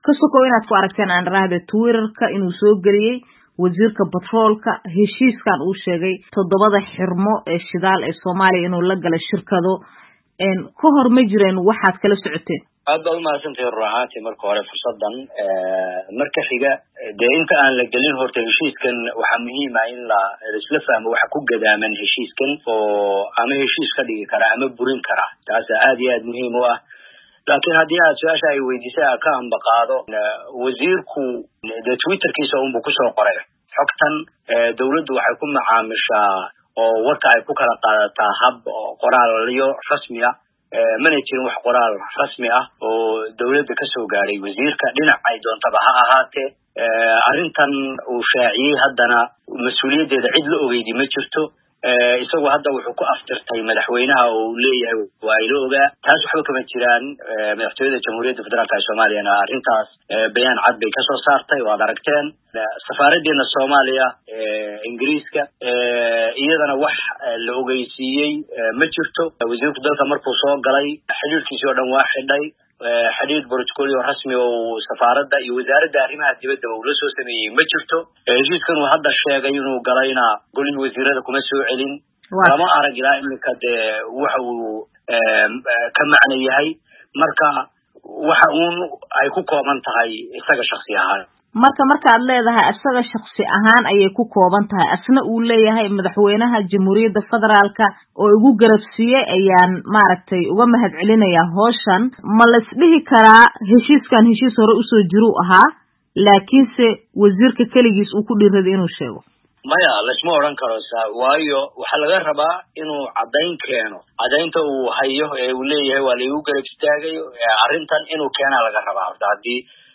Wareysi: Afhayeenka Farmaajo oo beeniyey hadalkii wasiirka Batroolka